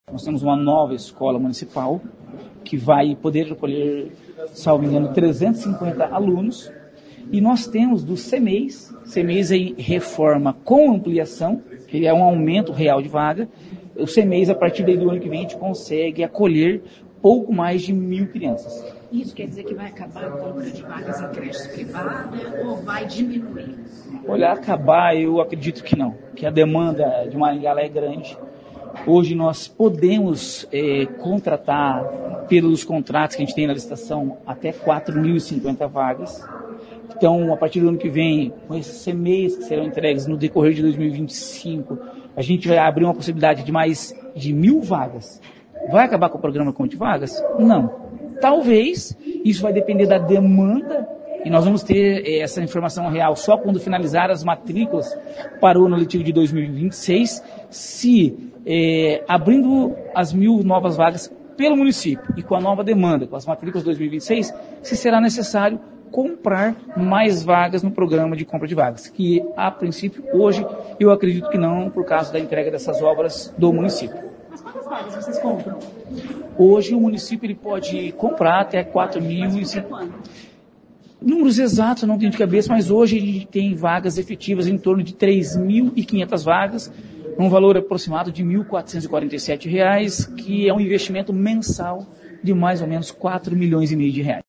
O secretário de Educação de Maringá, Fernando Brambilla, foi à Câmara Municipal nesta quinta-feira (13) para falar sobre as ações da secretaria e para responder perguntas de vereadores.
O secretário também falou sobre construção de novas unidades e abertura de mil vagas em Cmeis.